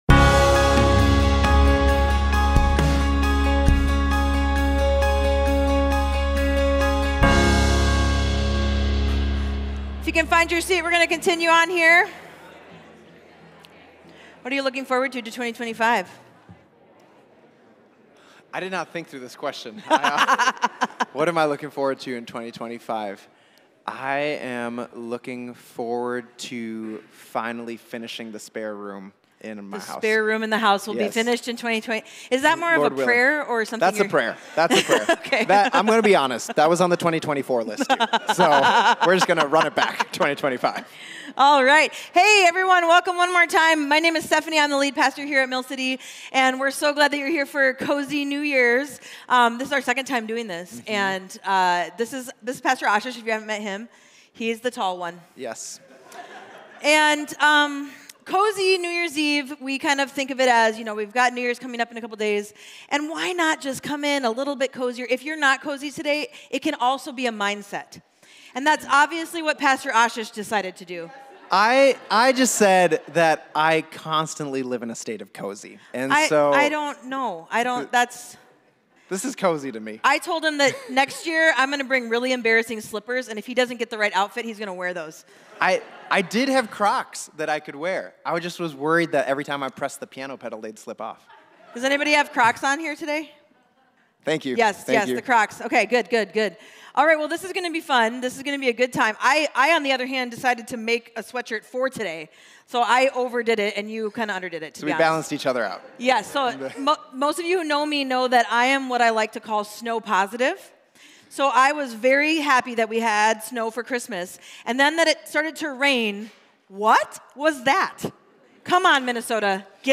Cozy Christmas Service